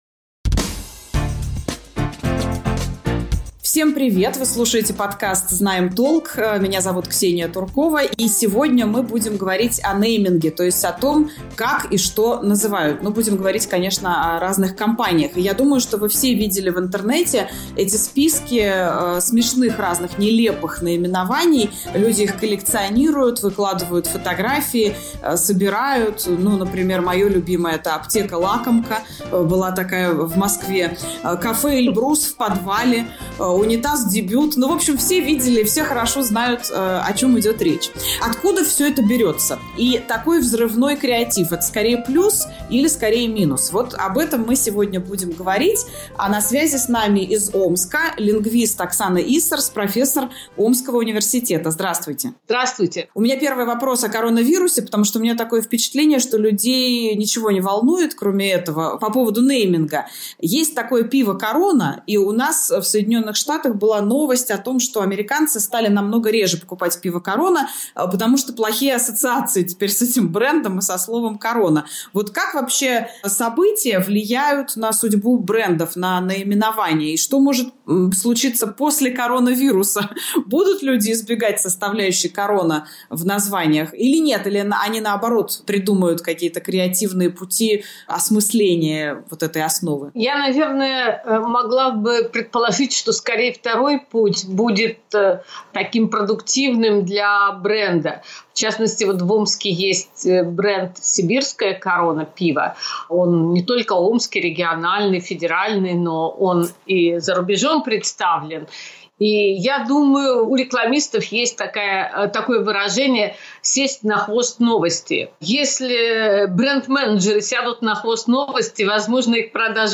Почему в российском нейминге так много абсурда? Разговор с лингвистом